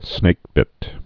(snākbĭt) also snake·bit·ten (-bĭtn)